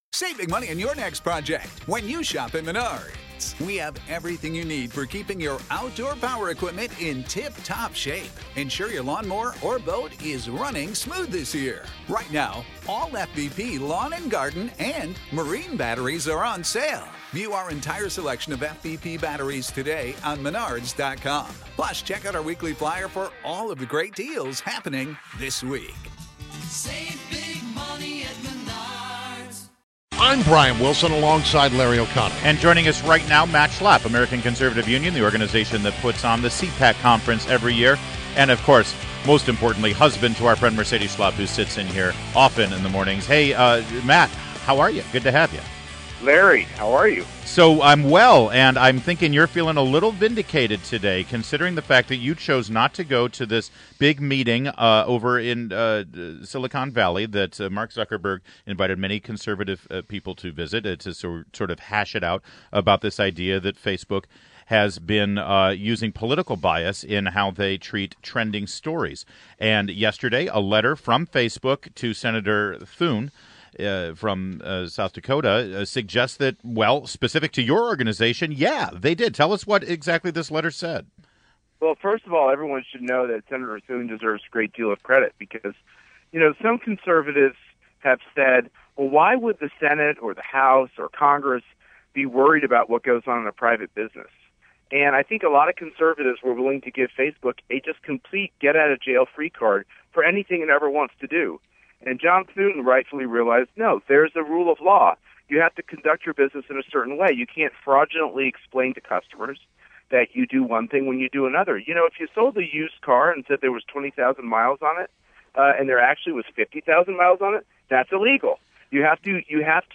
WMAL Interview - CPAC's MATT SCHLAPP 05.24.16
INTERVIEW – MATT SCHLAPP – Chairman of American Conservative Union, the organization that hosts the Conservative Political Action Conference (CPAC) every year